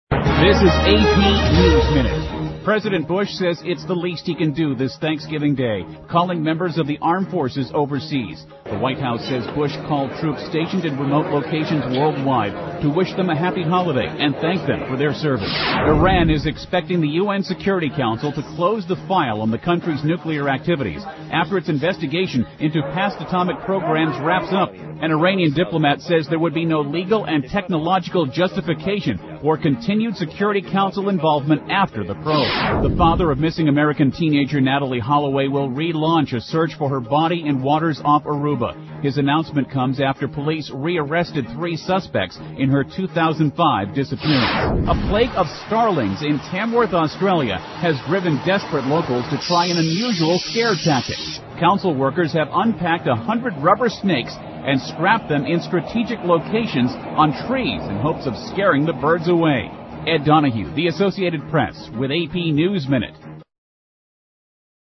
美联社新闻一分钟 AP NEWS 2007-11-23 听力文件下载—在线英语听力室